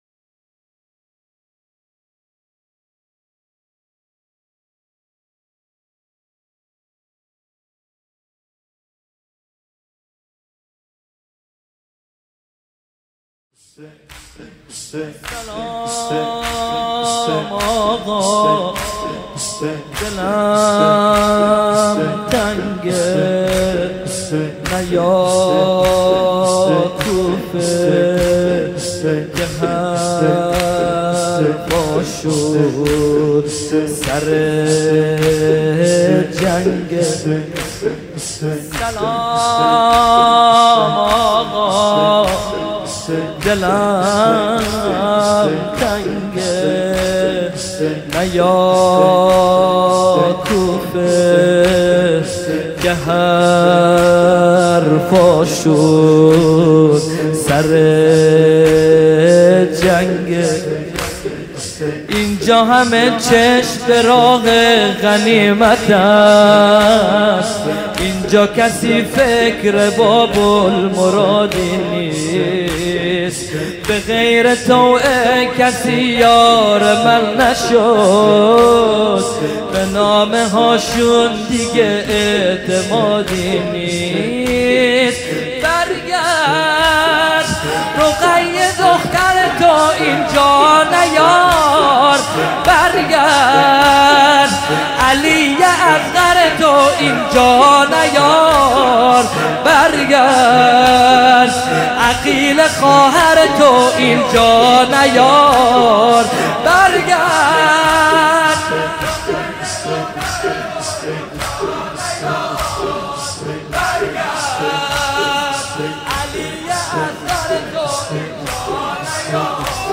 مداحی
شب اول محرم- سال 1439 هجری قمری